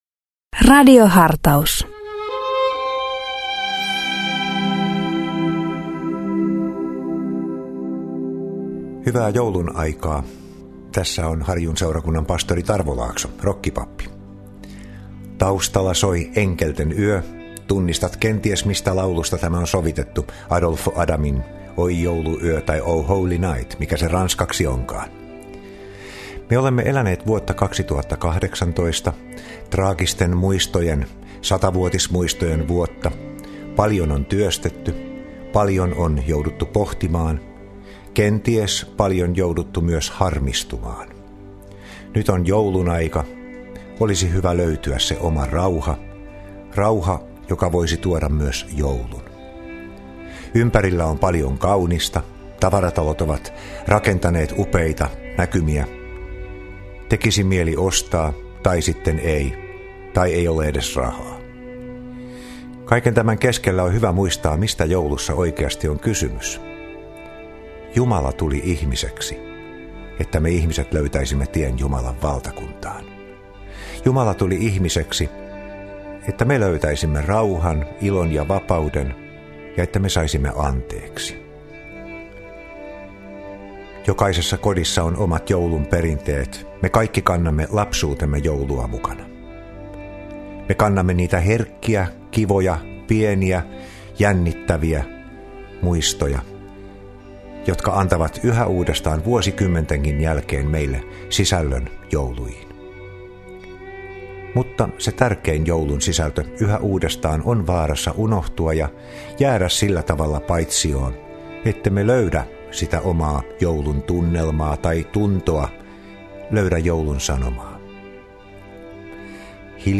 Radio Dei lähettää FM-taajuuksillaan radiohartauden joka arkiaamu kello 7.50. Hartaus kuullaan uusintana iltapäivällä kello 17.05.